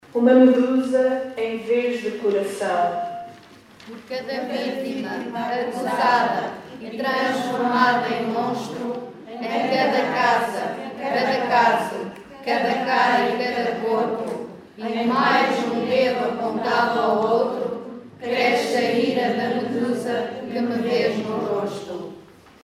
Também os idosos do projeto Idade Maior Idade Melhor deram o seu contributo, ao recitarem a letra da música Medusa, da conhecida rapper nacional Capicua, que fala sobre a violência doméstica.